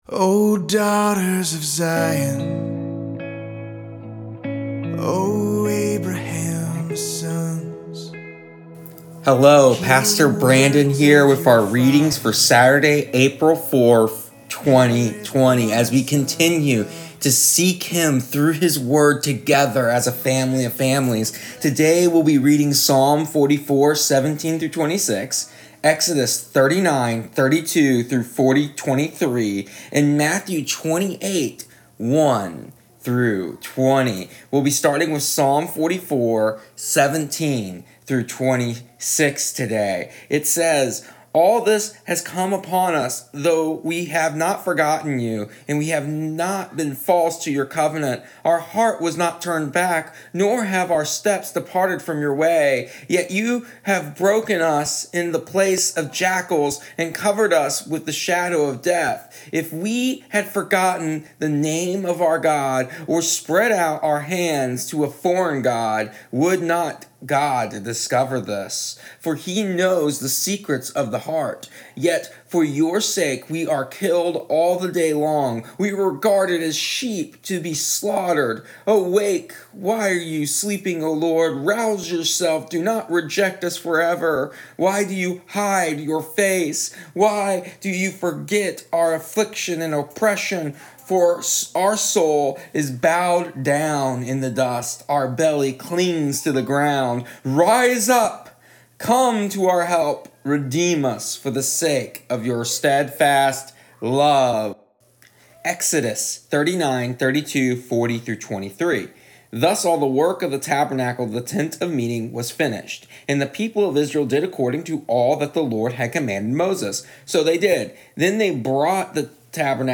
Here is our readings and audio devotional for April 4th. Today we discuss the beauty of the Great Commission and also finish up the Gospel of Matthew, so when we are able to once again gather in our family of families home make sure to sign of on the book.